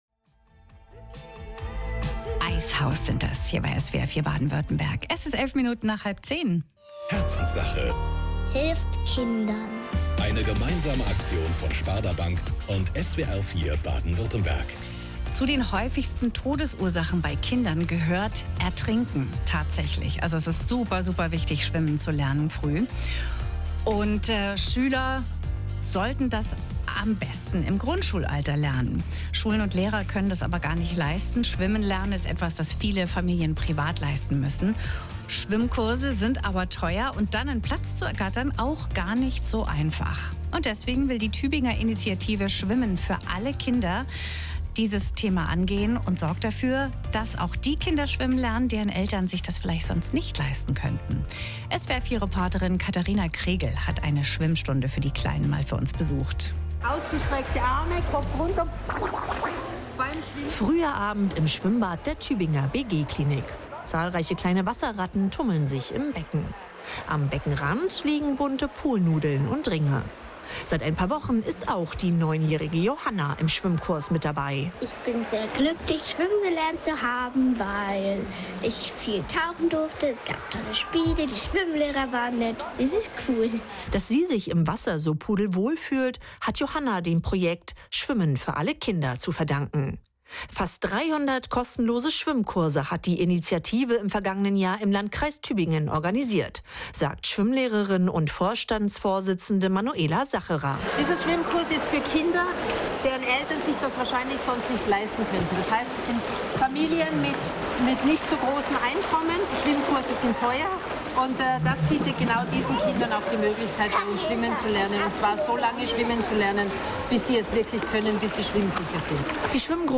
2025-02-18 SfaK - SWR4 Regionalnachrichten 12-30.mp3